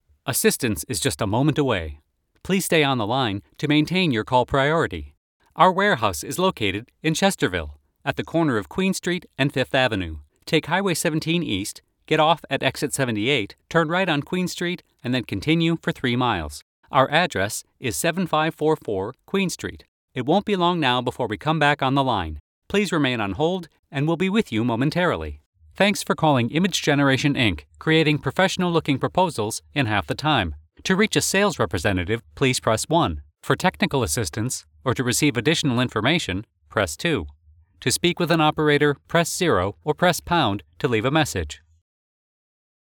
IVR Showreel
Male
American Standard
Confident
Friendly
Reassuring